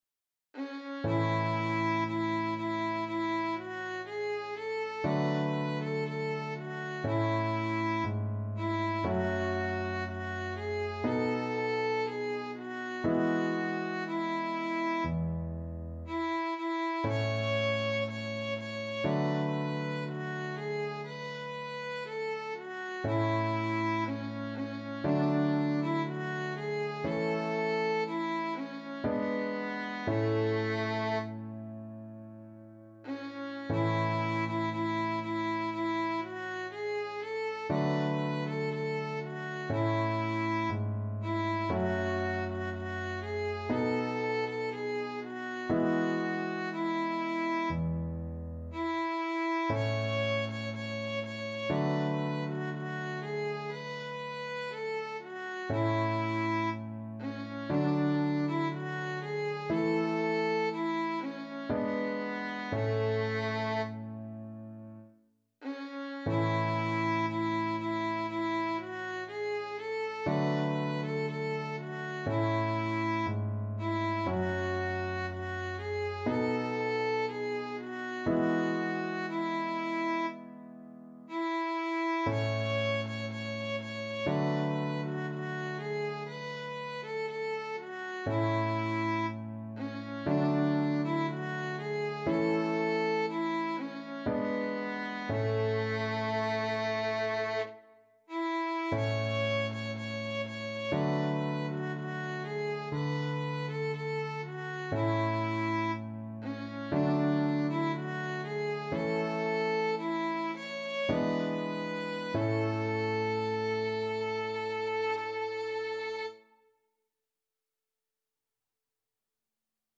Classical
4/4 (View more 4/4 Music)
~ = 100 Adagio
A4-Db6